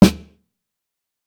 TC SNARE 16.wav